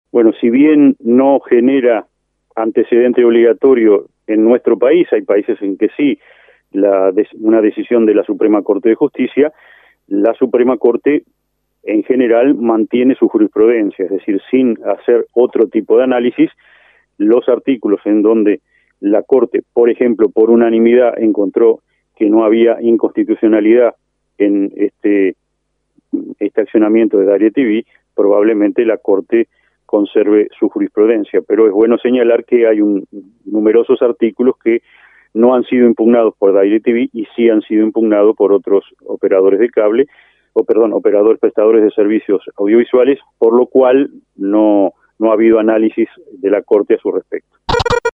El Ministro de la Suprema Corte de Justicia, Jorge Chediak, dijo a Rompkbzas que los artículos impugnados por DirecTV y que no fueron hallados inconstitucionales pueden caer en futuros análisis aunque sería un hecho fuera de lo habitual para la costumbre.